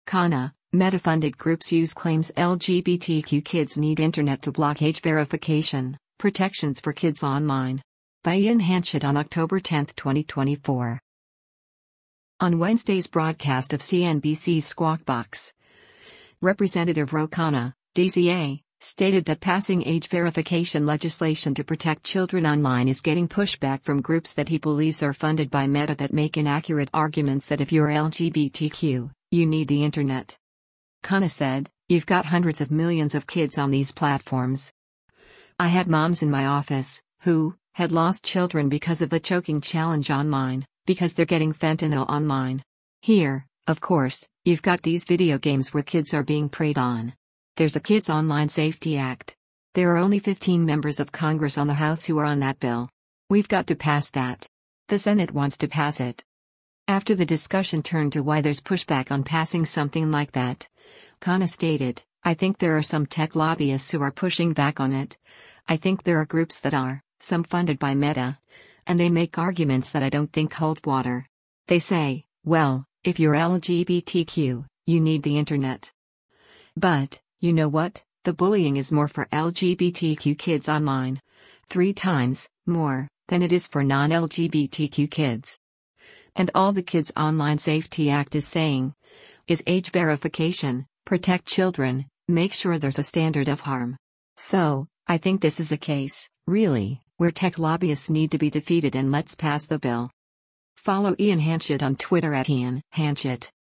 On Wednesday’s broadcast of CNBC’s “Squawk Box,” Rep. Ro Khanna (D-CA) stated that passing age verification legislation to protect children online is getting pushback from groups that he believes are funded by Meta that make inaccurate arguments that “if you’re LGBTQ, you need the Internet.”